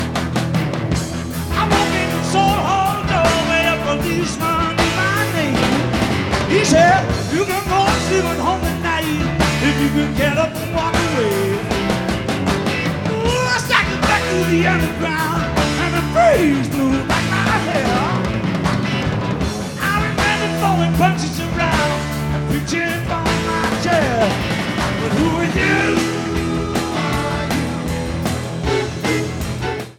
Portland (10-21-82) Radio Commercial(s) Master Tape